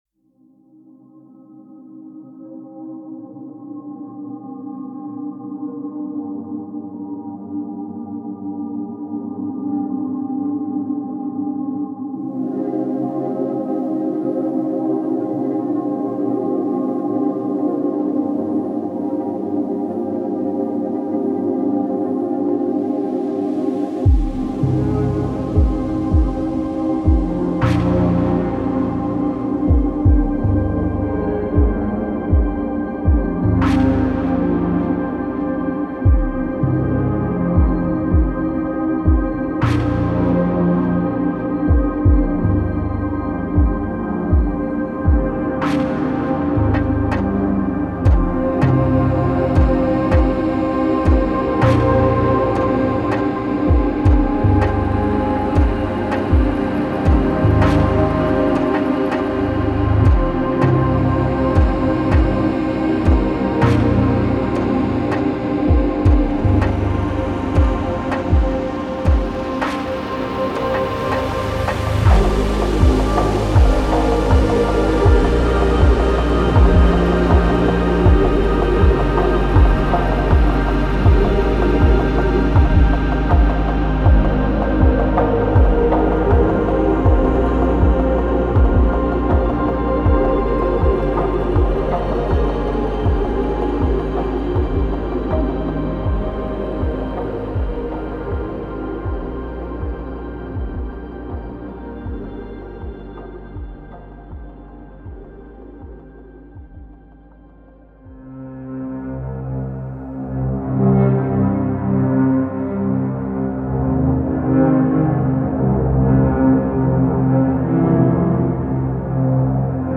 65 – 120 BPM
a nocturnal exploration of atmospheric electronica.